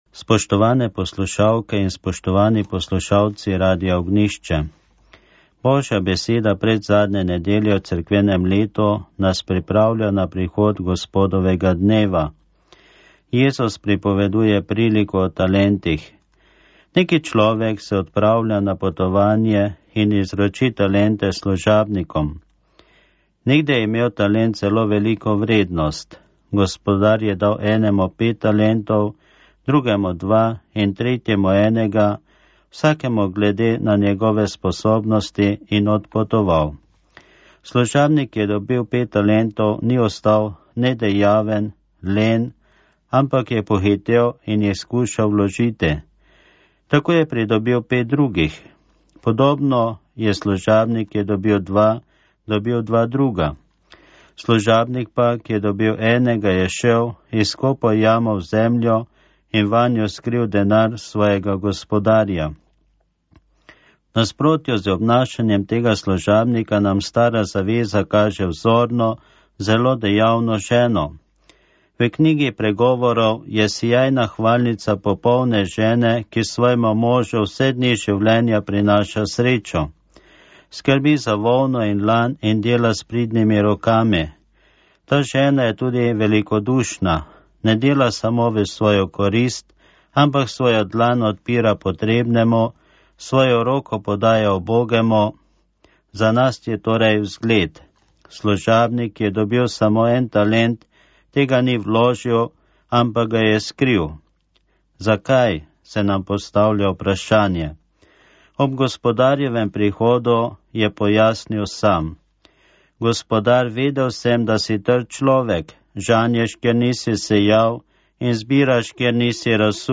Duhovni nagovor · 2008